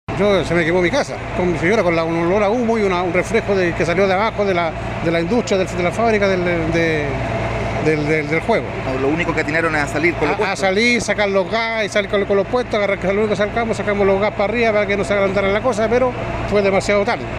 vecino.mp3